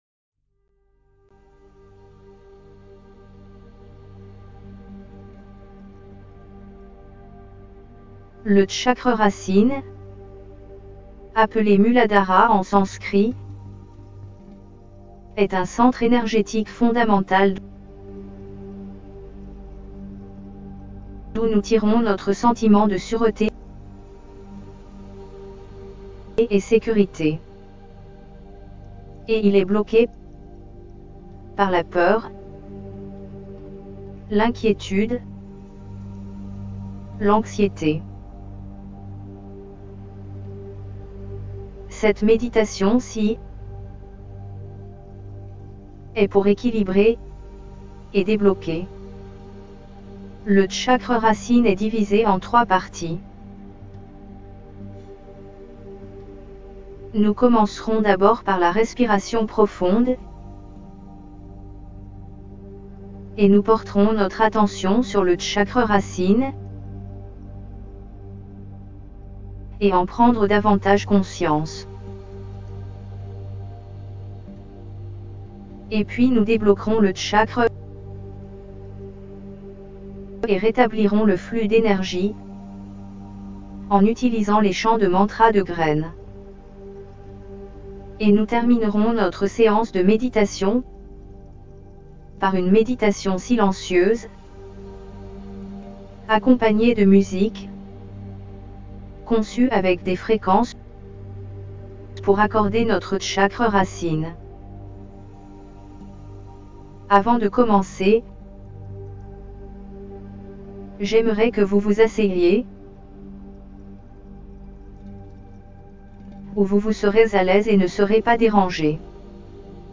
1RootChakraHealingGuidedMeditationFR.mp3